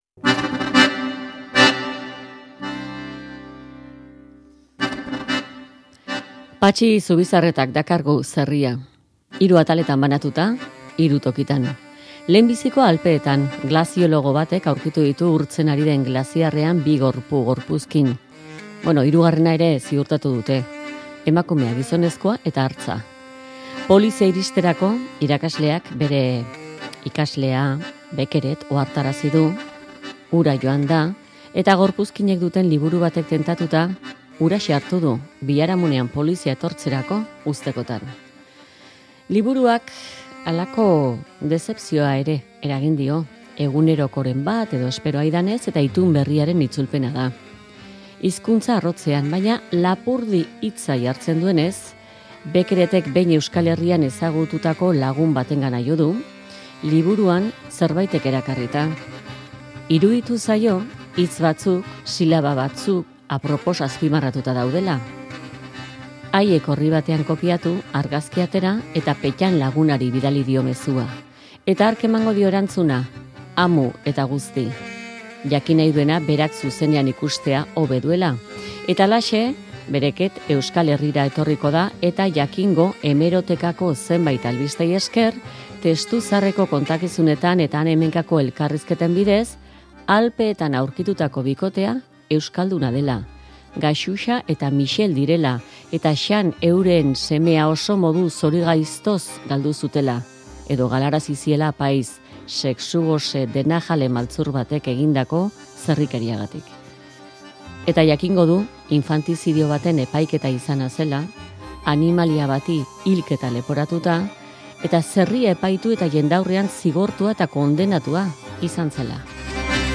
Play Rate Listened List Bookmark Get this podcast via API From The Podcast Gure herriaren jakintzaz gure fonotekan aukeratutako edukien artetik aukeratu ditugu zuretzat perla hauek elkarrizketa formatuko podcastetan Join Podchaser to...